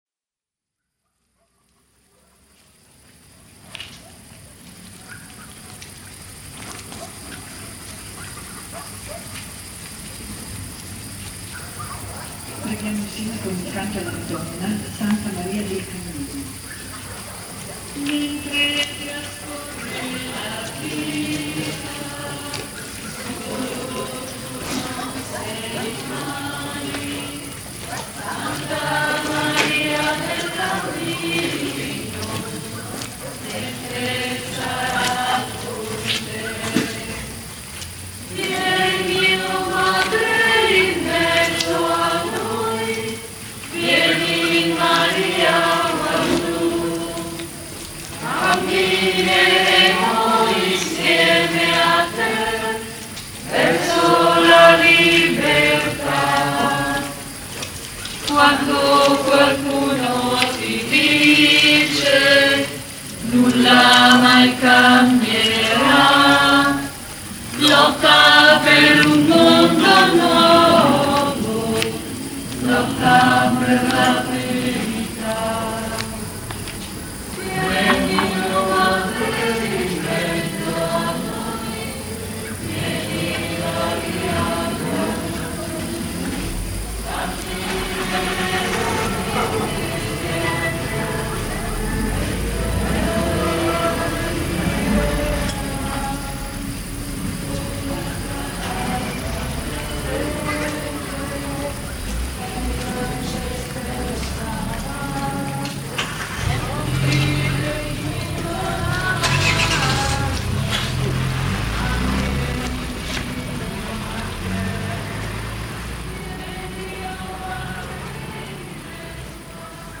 alternative rock
Finita la canzone si sente una sorta di inno al contrario.
intonato da fedeli all'aperto.